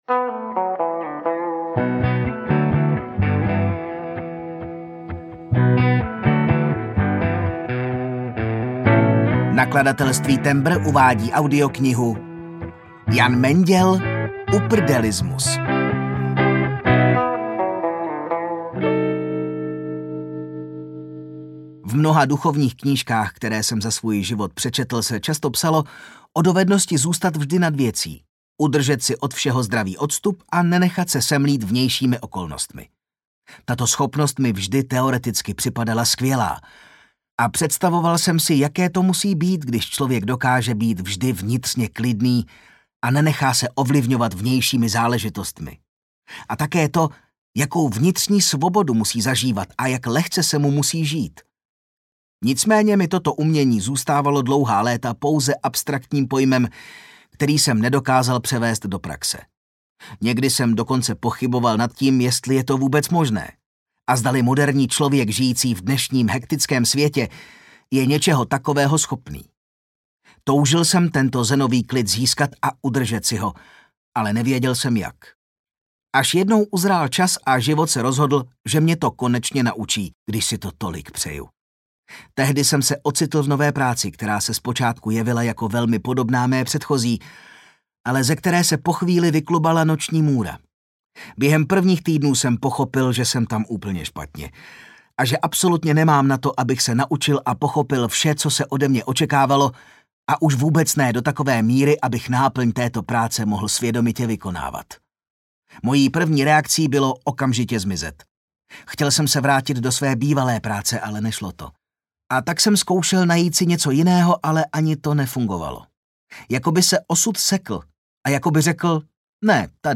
Uprdelismus audiokniha
Ukázka z knihy